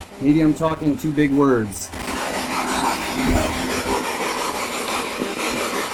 Spirit Box Clip 9 Villisca Axe Murder House Spirit Box Clip 9 This response came through our SB11 spirit box during a session in the upstairs kids' room closet.
Young child expresses gratitude to me A young child replies, "Thank you!" show/hide spoiler Back to Villisca Axe Murder House Evidence Page